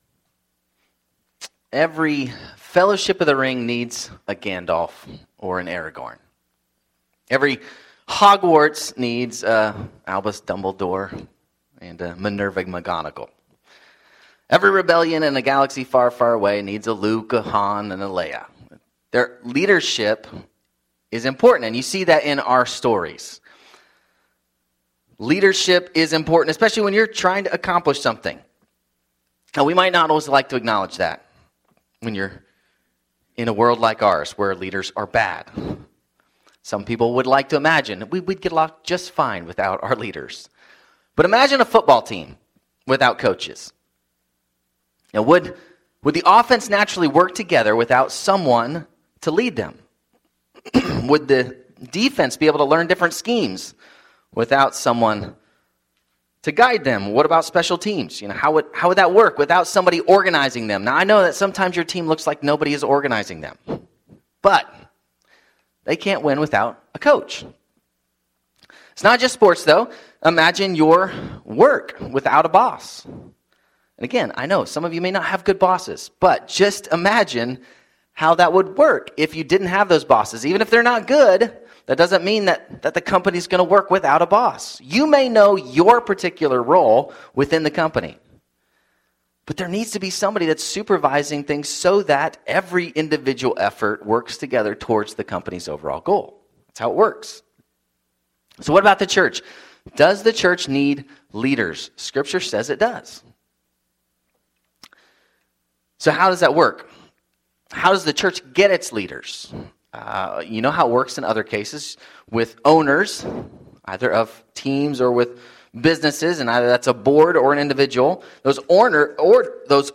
Sermons
Service Type: Sunday 10:30am